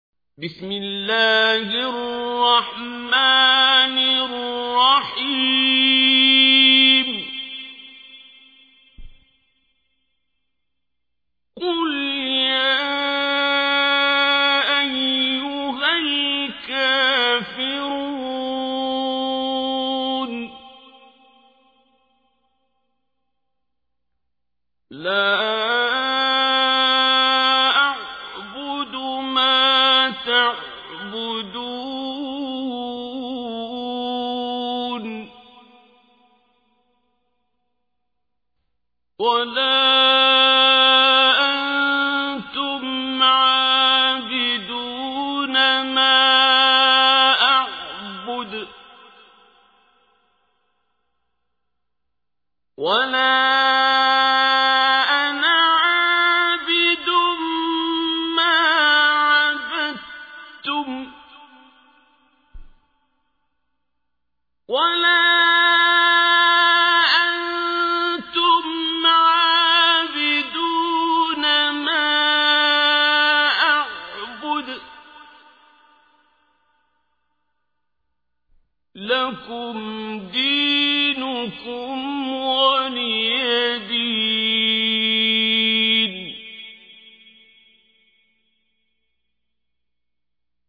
تحميل : 109. سورة الكافرون / القارئ عبد الباسط عبد الصمد / القرآن الكريم / موقع يا حسين